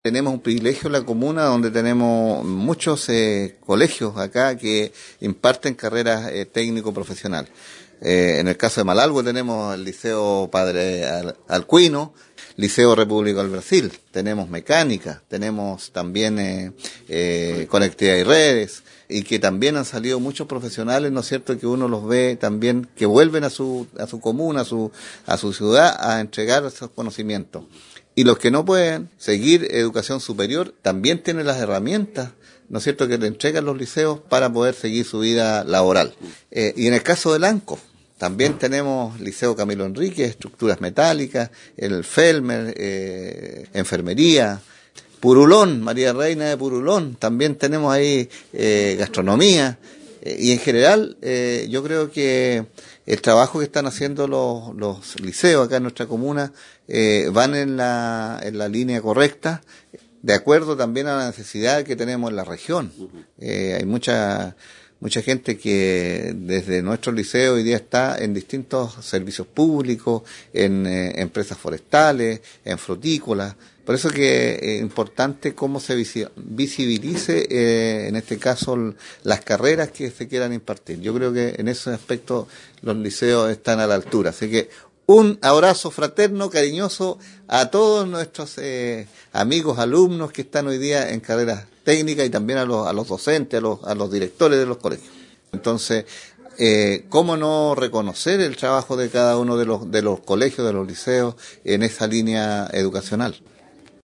En el contexto de la conmemoración del Día de la Educación Técnico Profesional, a efectuarse el 26 de agosto y ante las diversas actividades que desarrollan los liceos de la comuna de Lanco, el Alcalde Juan Rocha Aguilera, entregó un especial saludo a cada uno de los establecimientos educacionales de la comuna que entregan esta modalidad de enseñanza.
audio-alcalde.mp3